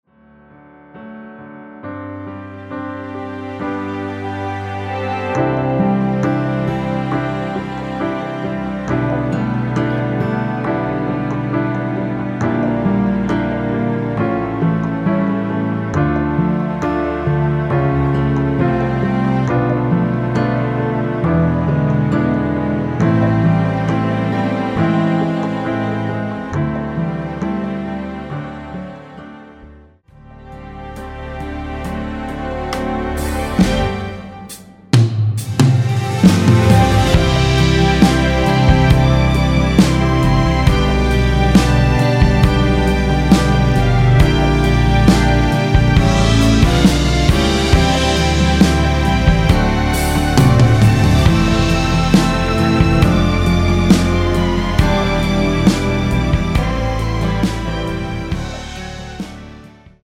전주가 길어서 미리듣기는 중간 부분 30초씩 나눠서 올렸습니다.